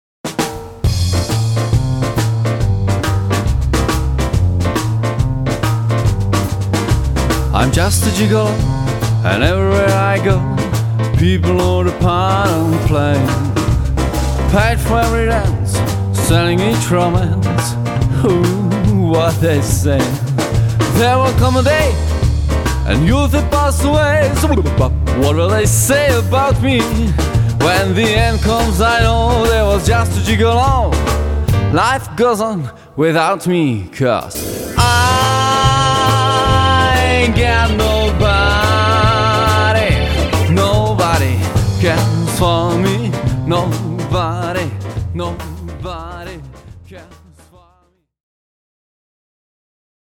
Milujeme jazz a swing -